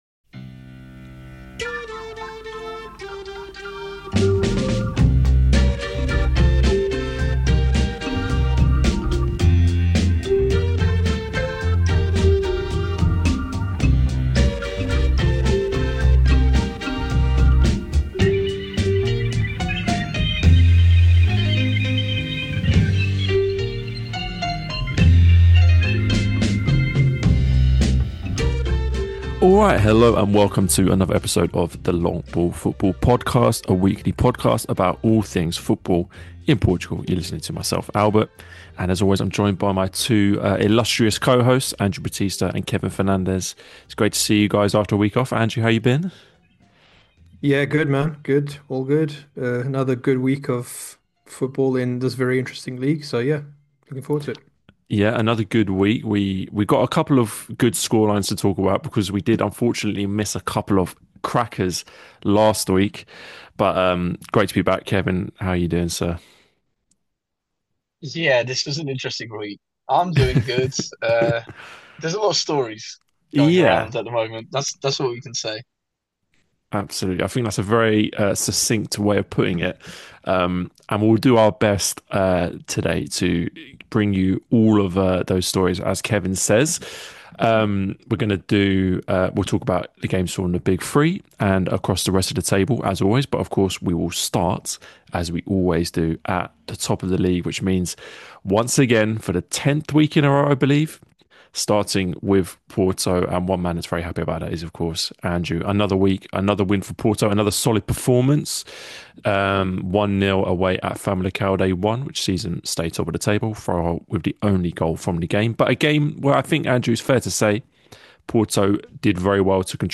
A weekly podcast by two brothers about all things football in Portugal 🇵🇹⚽🇬🇧 Join us each week for Primeira Liga chat, and discussion about Portuguese clubs' exploits in Europe!